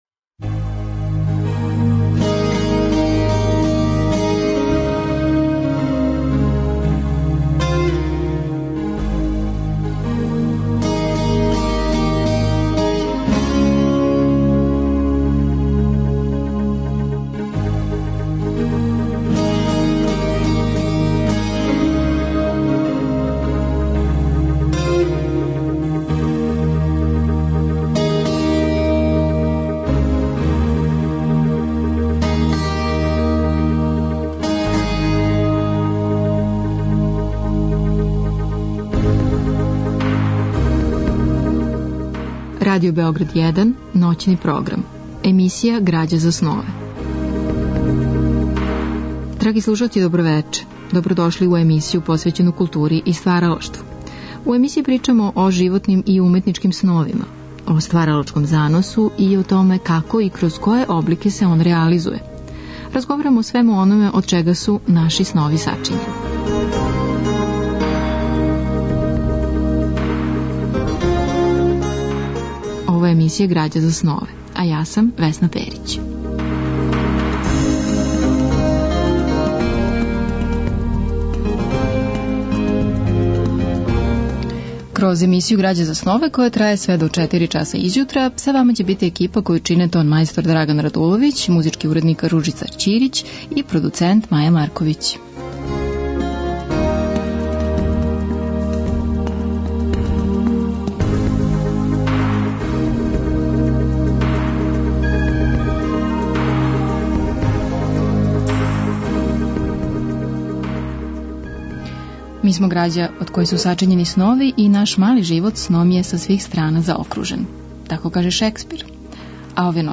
Разговор и добра музика требало би да кроз ову емисију и сами постану грађа за снове.
У другом делу емисије од 2 до 4 часа ујутро слушаћемо одабране делове биографских радио драма из продукције Драмског програма Радио Београда посвећених Ван Гогу, Гоји, Рембранту и Сави Шумановићу, као и одломке из студије Василија Кандинског „О духовном у уметности".